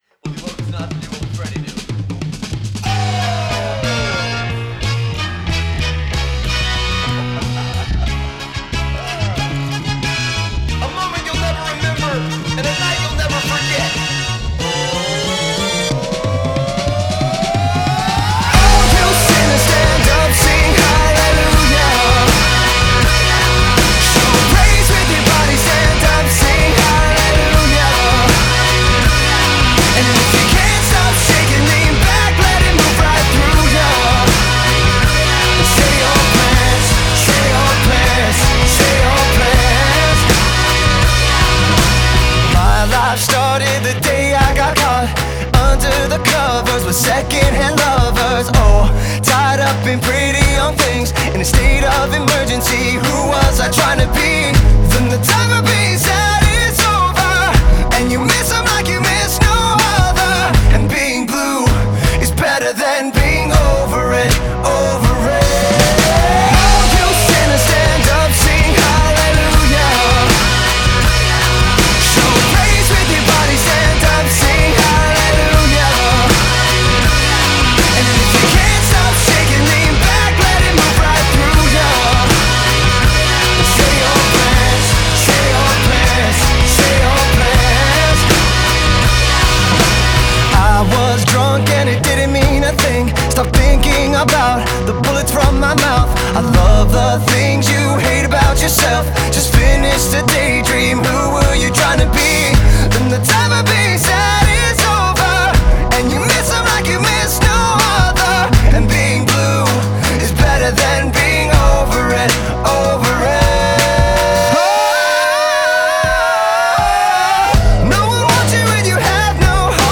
Genre: Alternative Rock, Pop Rock